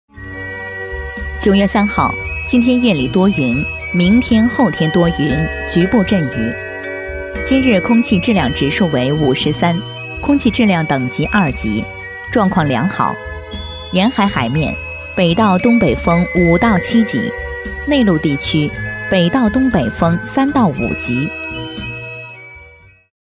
女174-天气预报
女174-磁性端庄 纪实记录
女174-天气预报.mp3